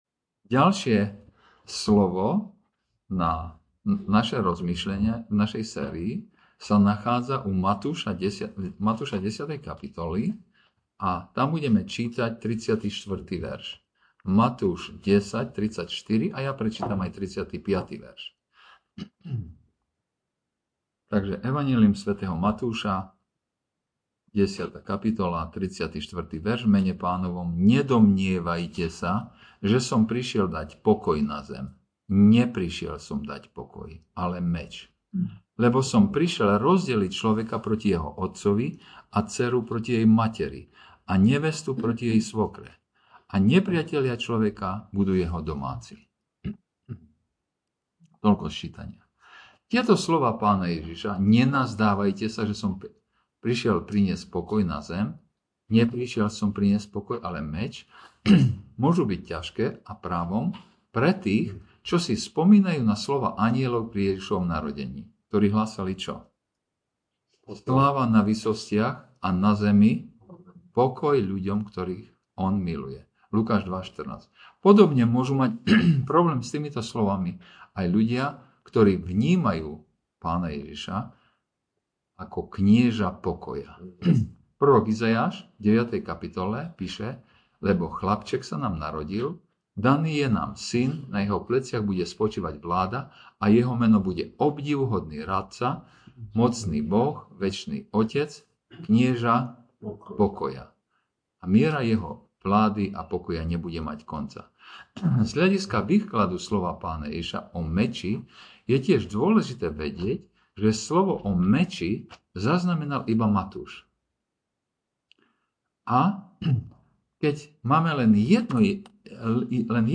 Vyučovanie - Košice Baptist - cirkevný zbor